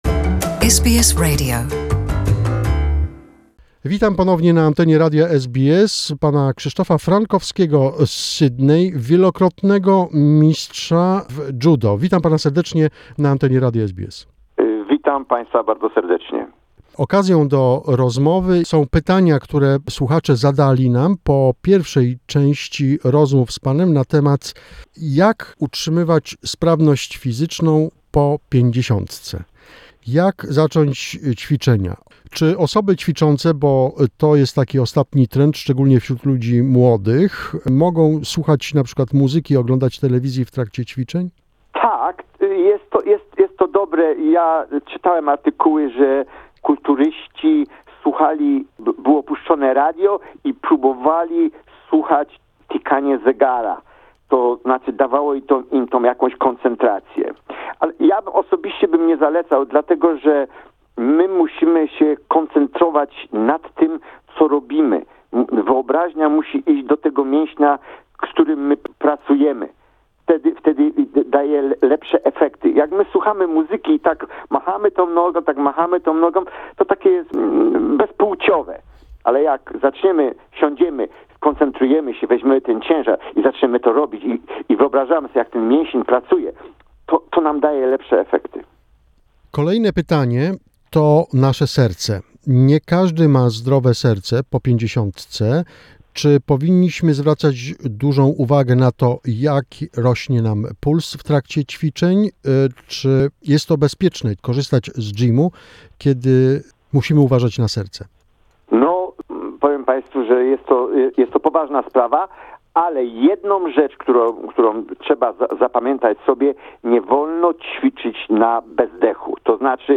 This is a part 3 of the interview.